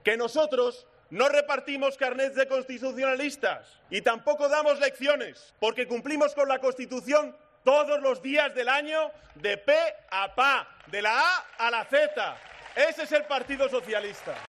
"Hoy se están manifestando en contra de un Gobierno socialista. Pues lo siento. ¡Va a haber un gobierno socialista!", ha dicho en su discurso en la Fiesta de la Rosa en Gavà (Barcelona), en la que también ha intervenido el primer secretario del PSC, Salvador Illa, y la alcaldesa Gemma Badia.